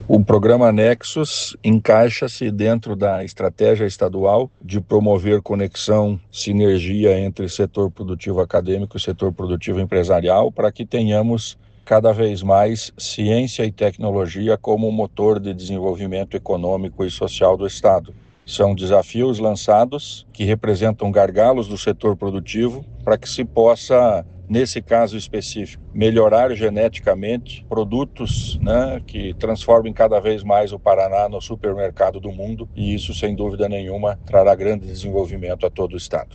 Sonora do secretário da Ciência, Tecnologia e Ensino Superior, Aldo Bona, sobre o lançamento do programa Nexus Inovação Aberta